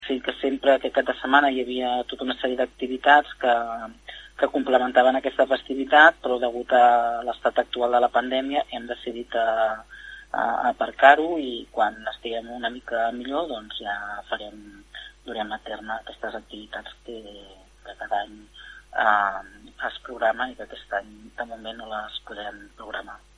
Susanna Pla és regidora de Cultura de l’Ajuntament de Palafolls.